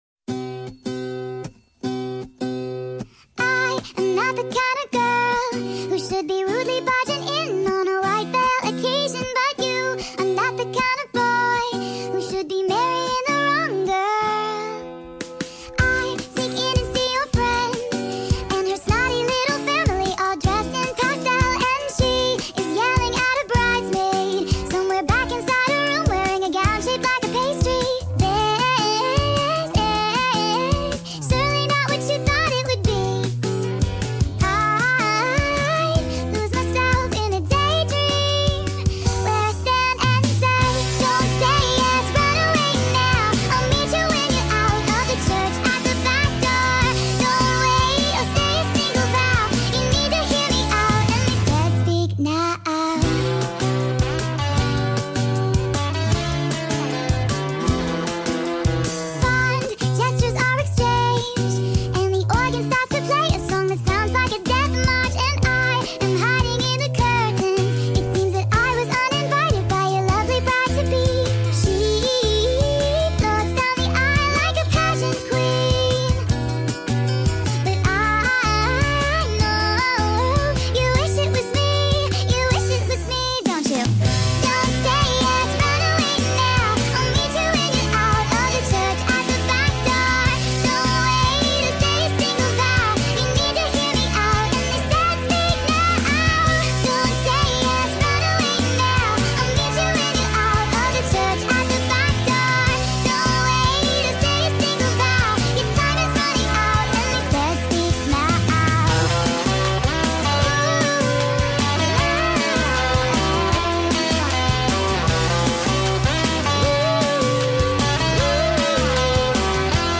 SONG SPED UP AUDIO!